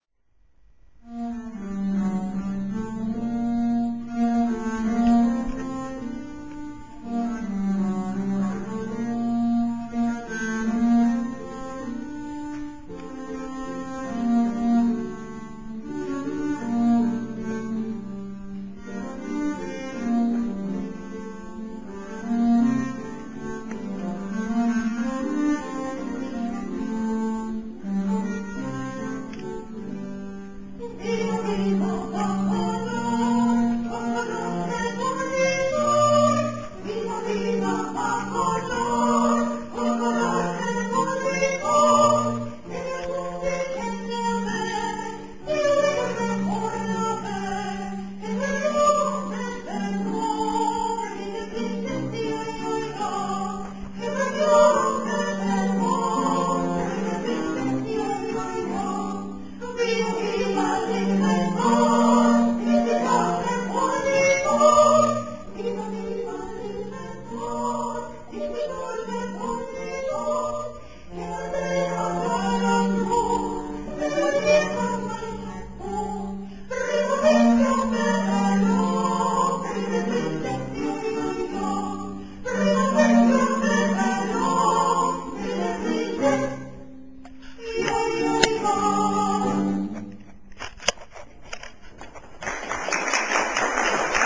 17. komorní koncert na radnici v Modřicích
Anglická, italská a španělská renesance - rozverné zpěvy, loutna a viola da gamba - tak nějak se bavili lidé před 400 lety ...
Amatérský záznam, jako ukázka skladeb z koncertu - formát .WMA: